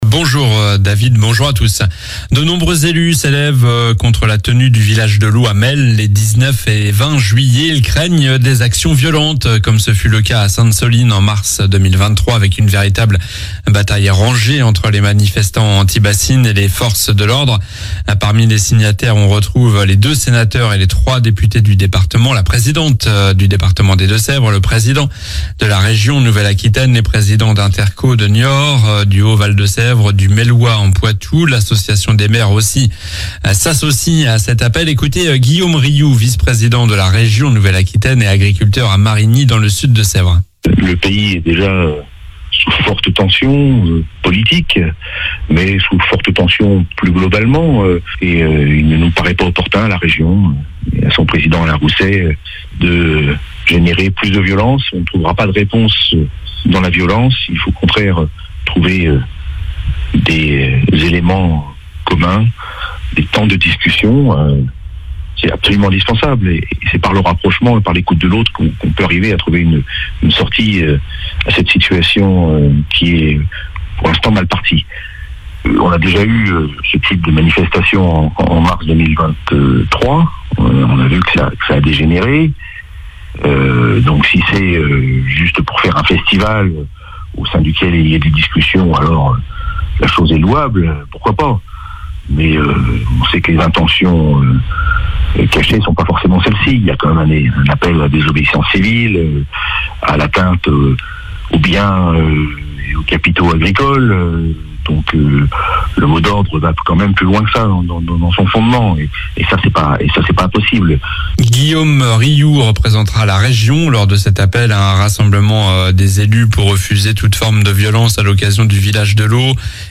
COLLINES LA RADIO : Réécoutez les flash infos et les différentes chroniques de votre radio⬦
L'info près de chez vous